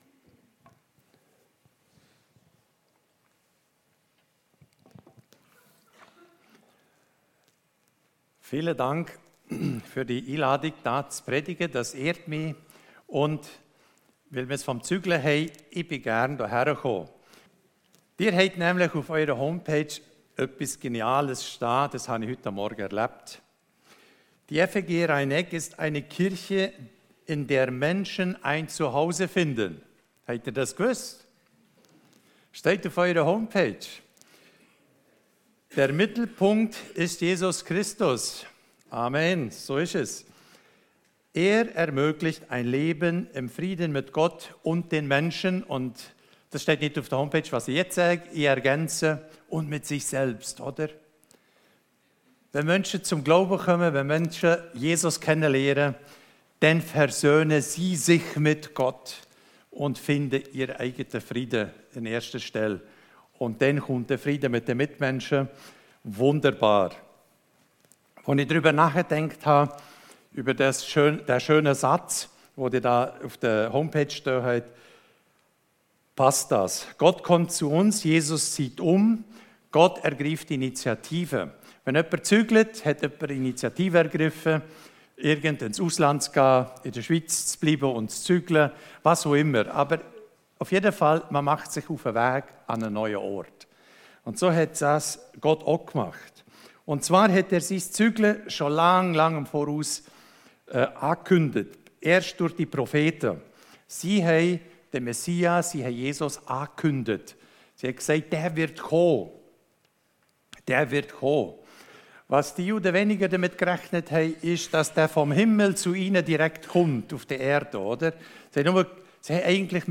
Predigt
Hier hörst du die Predigten aus unserer Gemeinde.